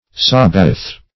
Sabaoth \Sab"a*oth\ (s[a^]b"[asl]*[o^]th or s[.a]"b[=a]*[o^]th;